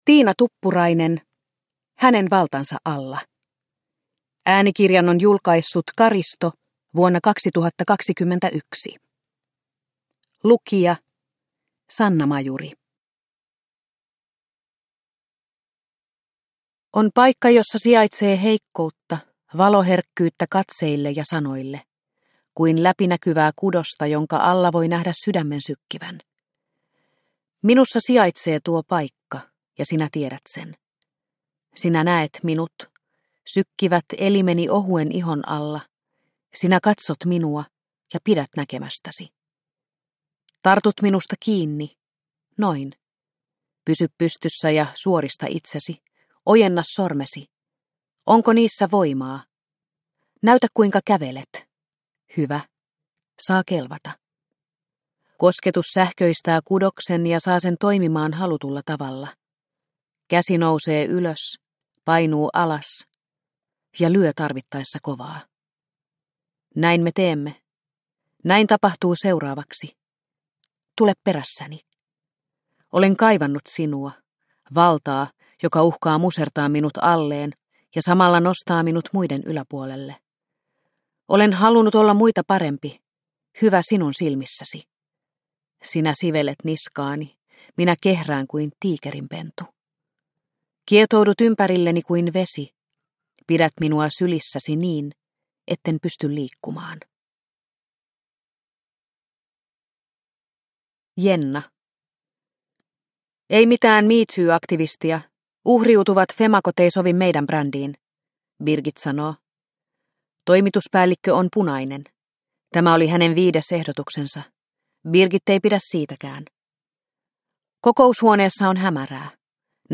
Hänen valtansa alla – Ljudbok – Laddas ner